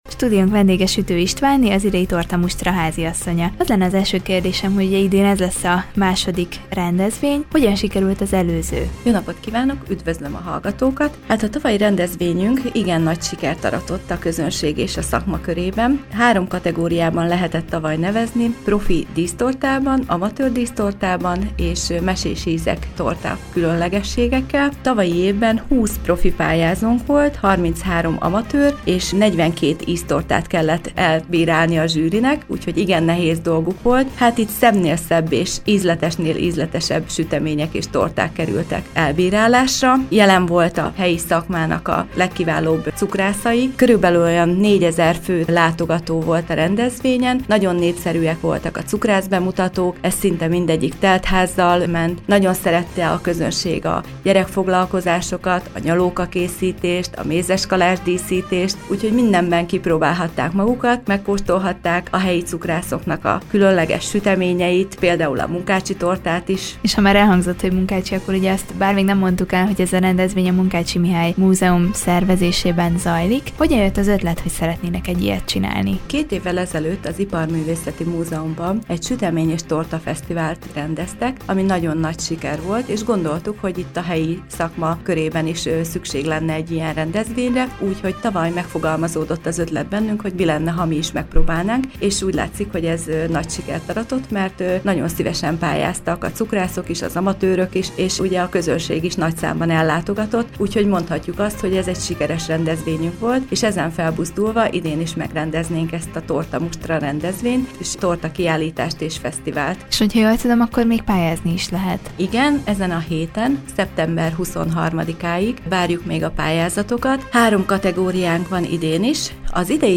Vele beszélgetett tudósítónk az idén második alkalommal megrendezésre kerülő Tortamustráról valamint a fesztivál programjairól.